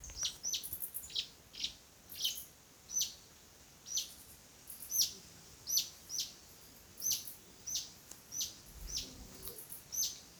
Ochre-cheeked Spinetail (Synallaxis scutata)
Location or protected area: Parque Nacional Calilegua
Condition: Wild
Certainty: Observed, Recorded vocal